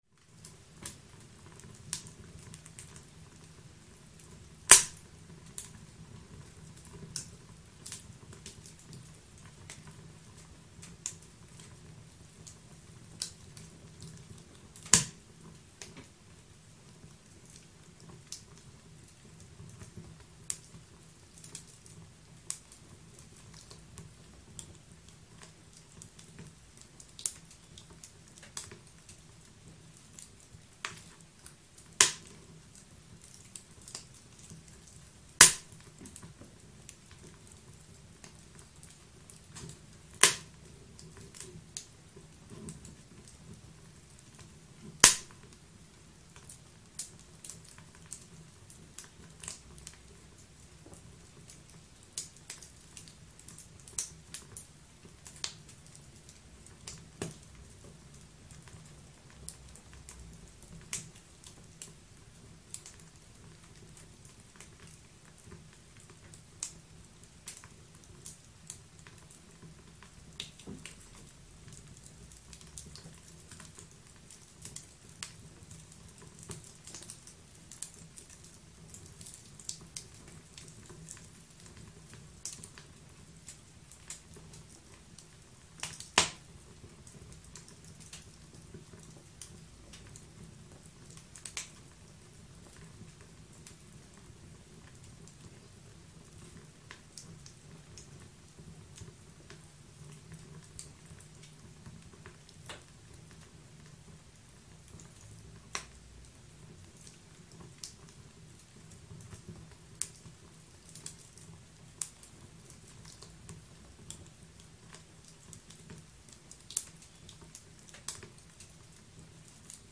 صدای طبیعت: سوختن چوب، باران و باد
صدای سوختن چوب، باران و باد
گوش دادن به صدای طبیعت آرام‌بخش و تسکین‌دهنده روح و جسم آدمیست.